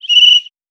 Whistle Short Blow.wav